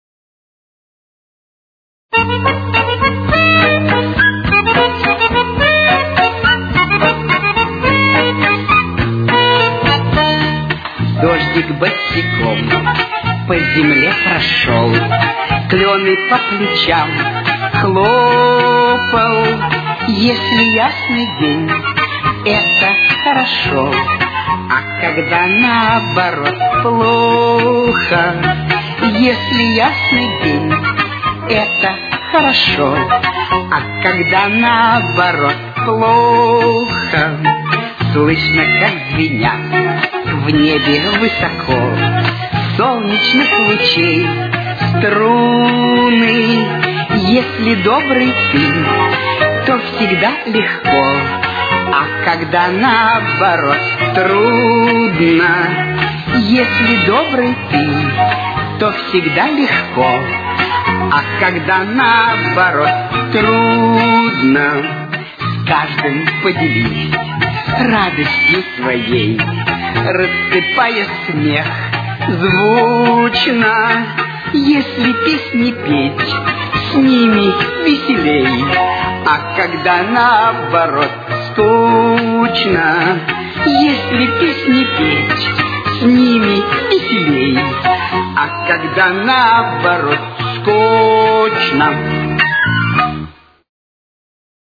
Темп: 109.